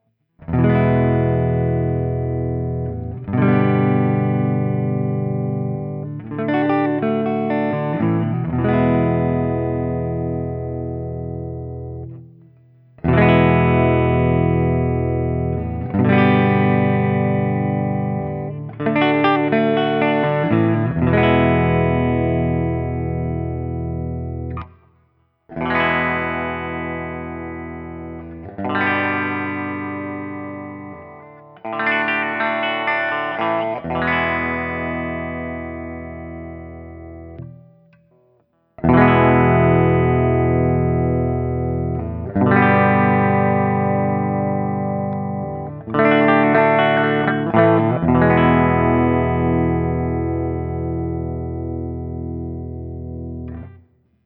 ODS100 Clean
Open Chords #1
As usual, for these recordings I used my normal Axe-FX II XL+ setup through the QSC K12 speaker recorded direct into my Macbook Pro using Audacity.
For each recording I cycle through the neck pickup, both pickups, both pickups with phase reversed on the neck, and finally the bridge pickup.
Guild-Nightbird-I-ODS100-Open1.wav